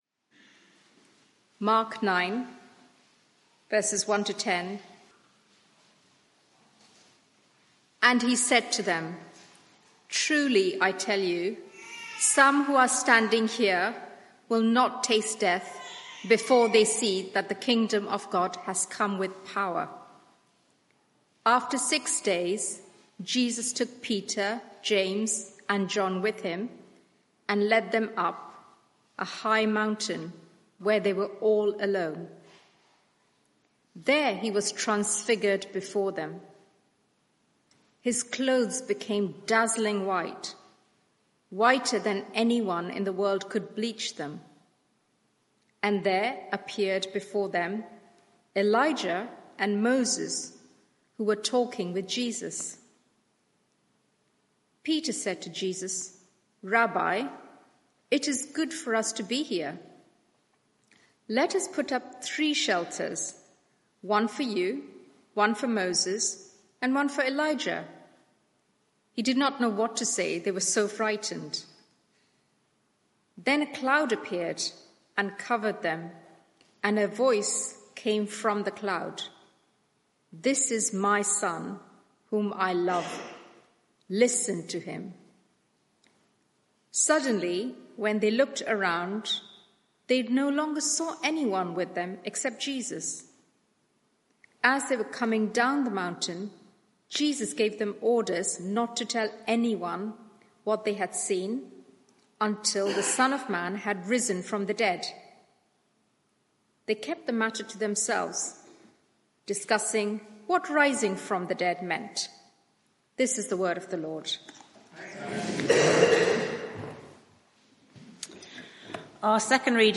Media for 6:30pm Service on Sun 08th Sep 2024 18:30 Speaker
Passage: 2 Peter 1:12-21 Series: Stable and growing Theme: Sermon